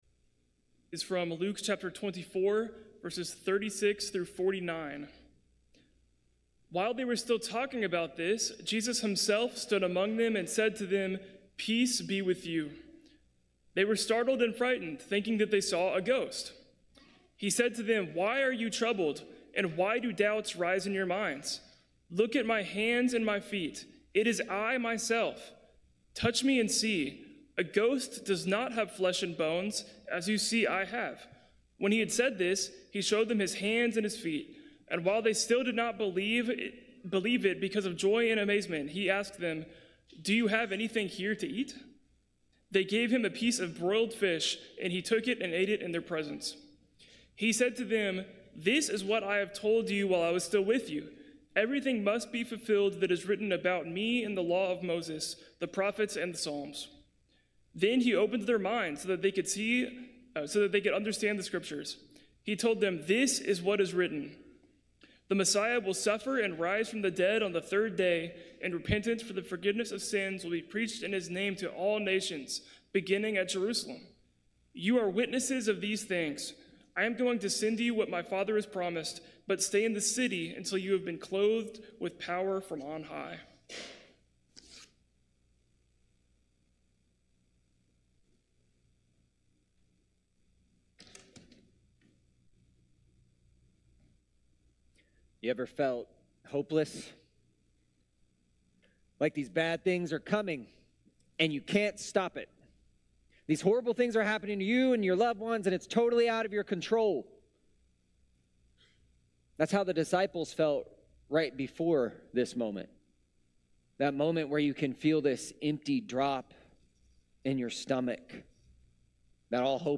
Peace-Be-With-You-Easter-Sunday.mp3